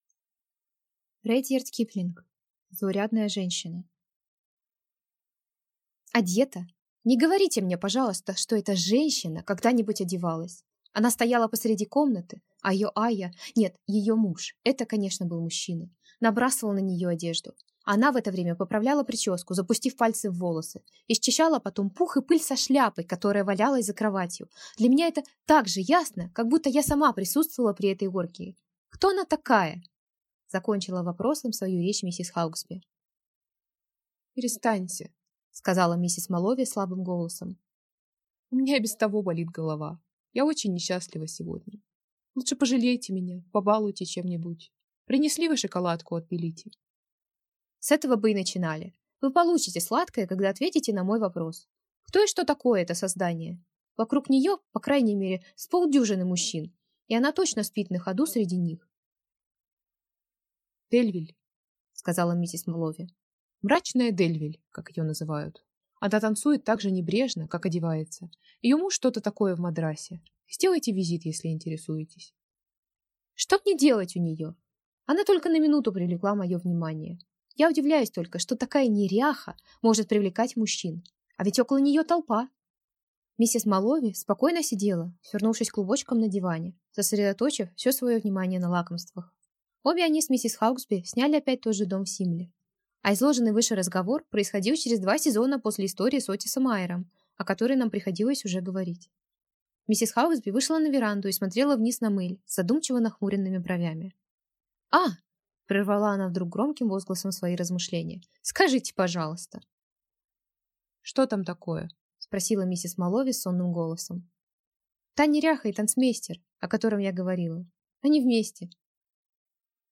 Аудиокнига Заурядная женщина | Библиотека аудиокниг